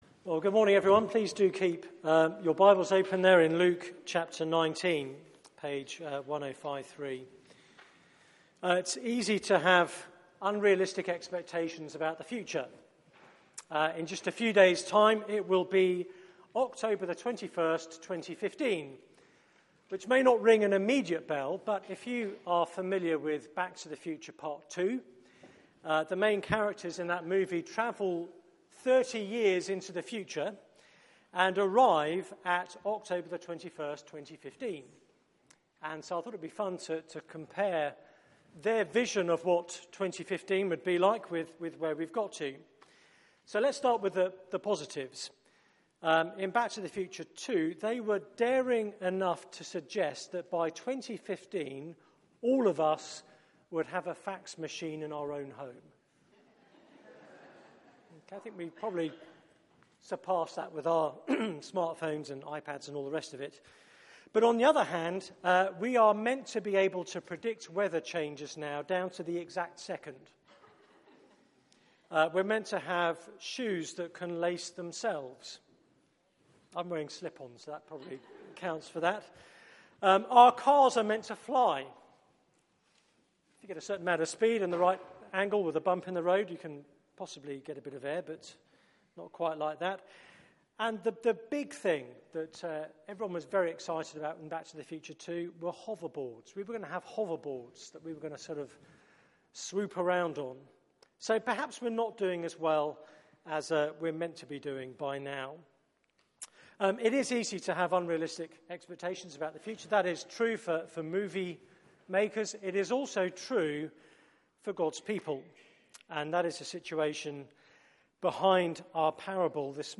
Media for 9:15am Service on Sun 11th Oct 2015
Theme: Subjects, Servants and the King Sermon